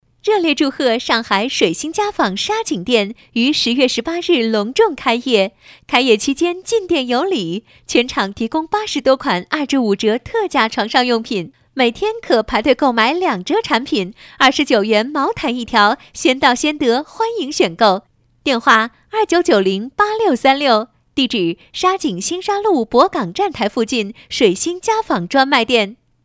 女国12温柔舒缓配音-新声库配音网
女国12_广告_促销_水星家纺_甜美.mp3